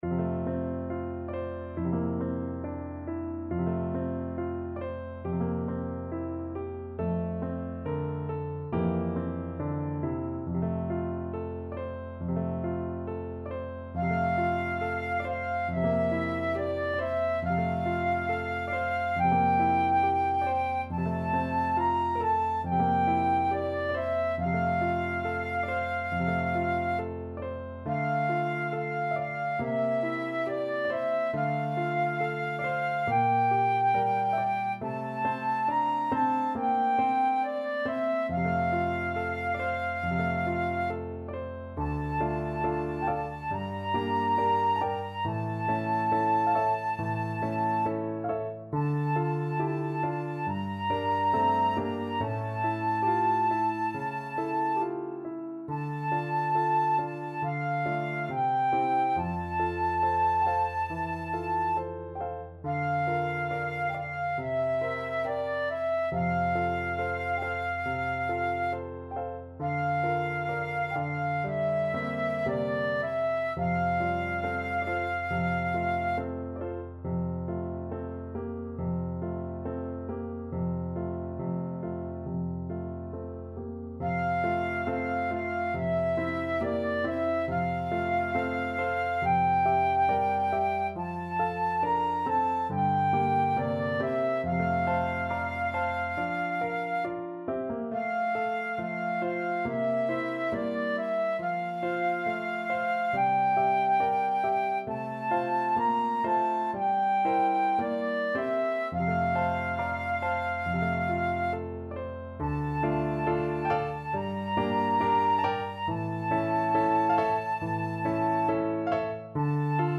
Flute version
~ = 69 Andante tranquillo
Flute  (View more Easy Flute Music)
Classical (View more Classical Flute Music)